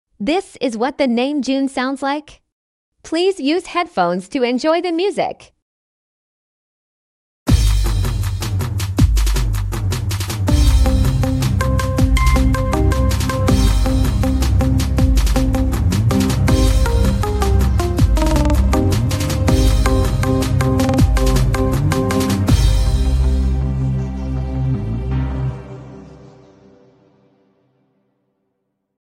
How the name June sounds like as midi art.